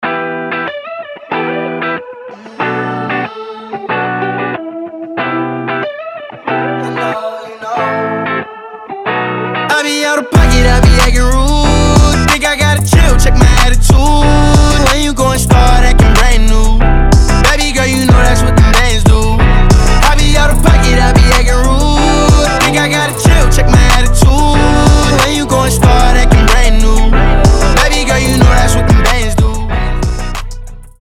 мужской голос
крутые
качающие
озорные